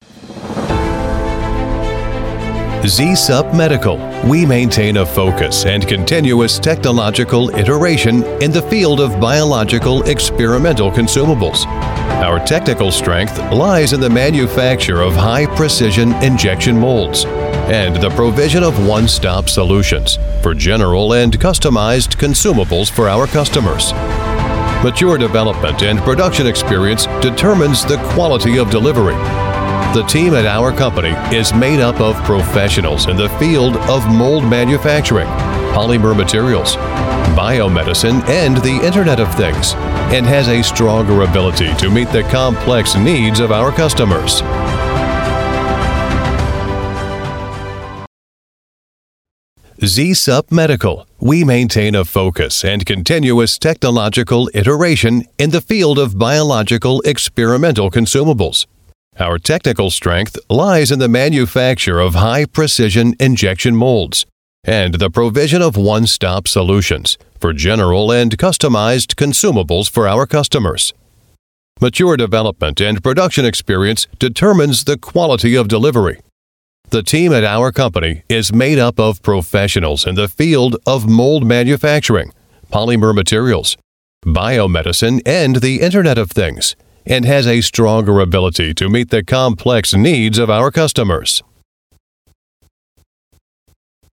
Voice Samples: Medical Company Introduction
EN US
male